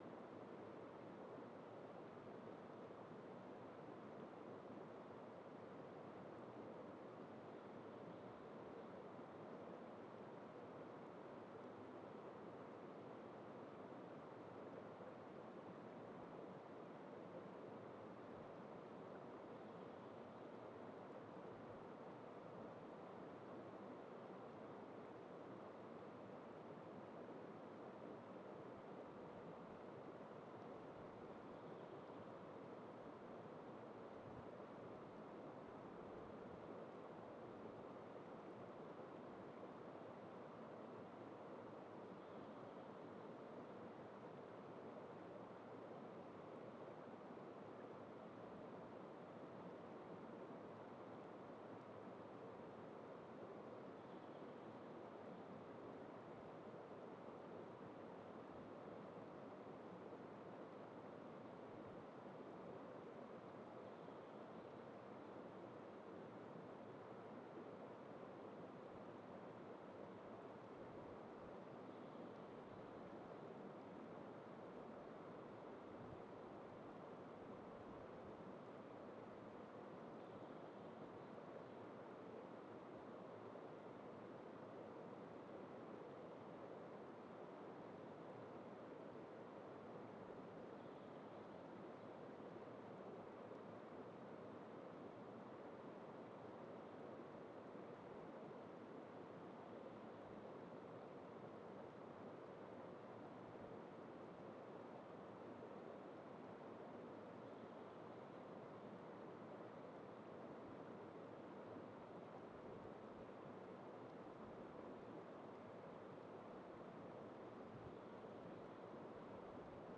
Bänder geteiltes Rauschen
Quellrauschen in Bänder geteilt 125.wav